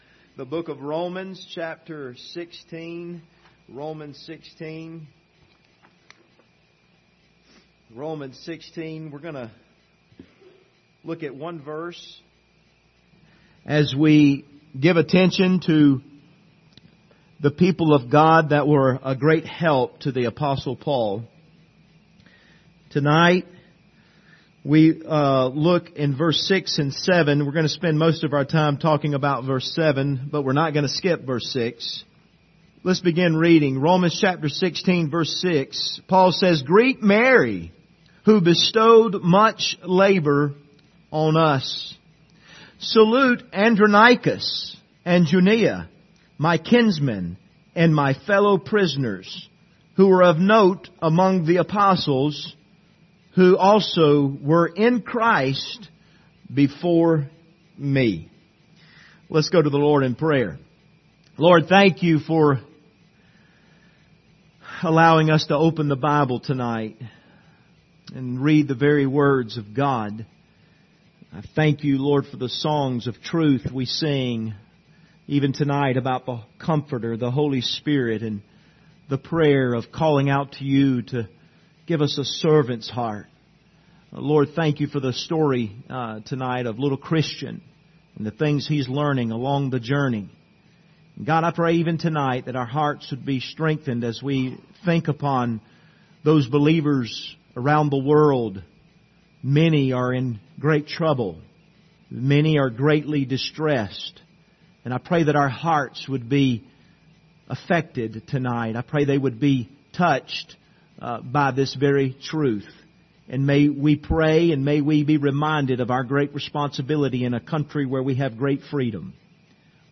The Just Shall Live By Faith Passage: Romans 16:6,7 Service Type: Sunday Evening « Wait